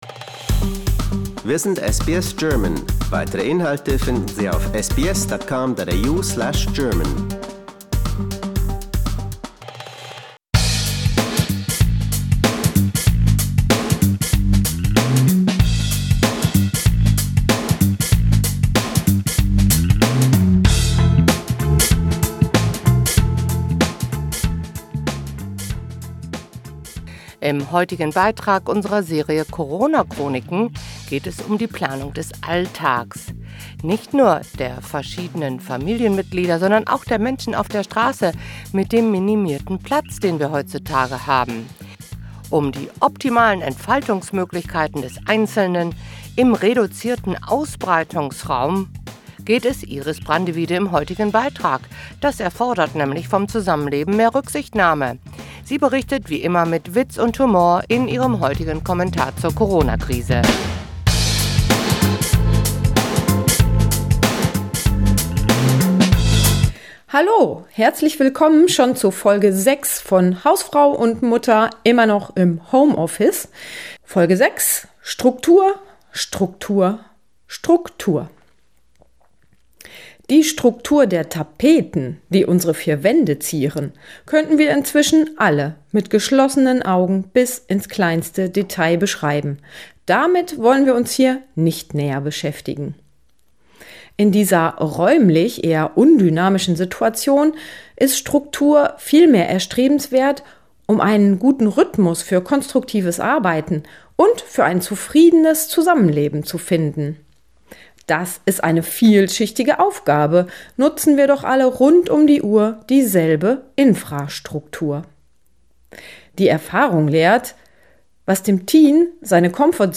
wie immer mit Witz und Humor in ihrem heutigen Kommentar zur Corona Krise.